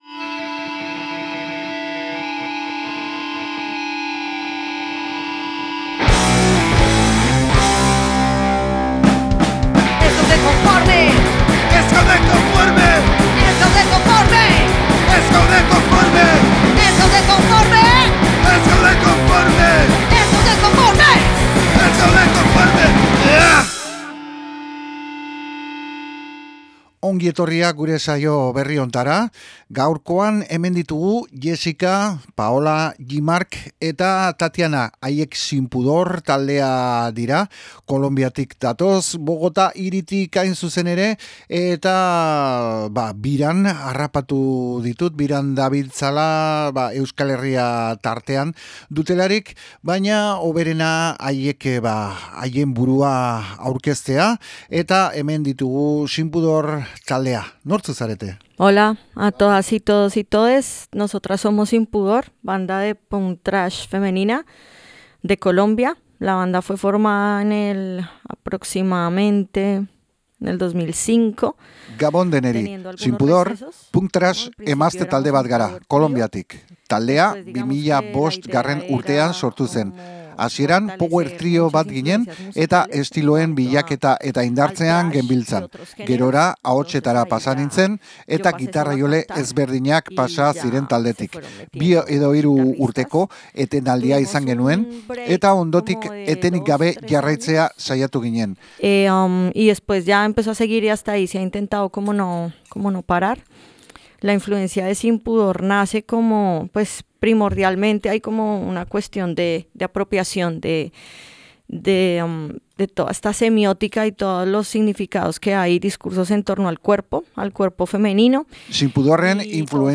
SIN PUDOR, Bogotako talde Kolonbiarra gure estudioetatik pasa da, elkarrizketa mamitsua emanik, ondotik haien zuzeneko indartsua erakutsiko dutelarik.